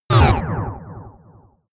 Gemafreie Sounds: Laser